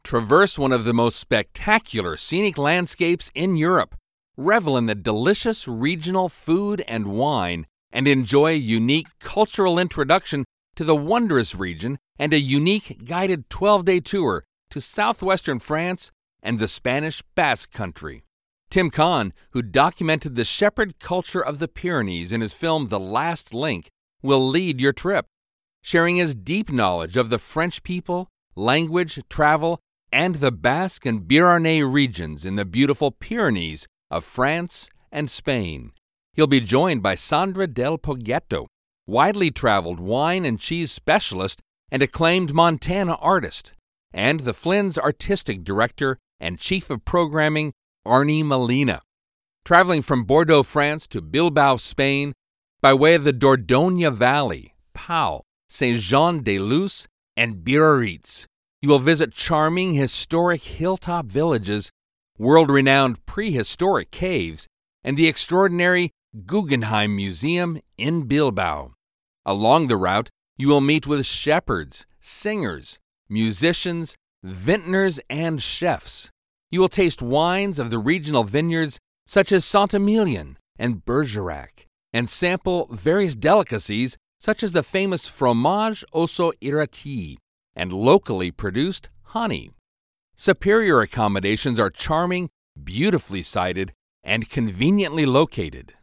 Male
Adult (30-50), Older Sound (50+)
Tour Guide